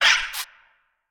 Sfx_creature_babypenguin_flinch_land_02.ogg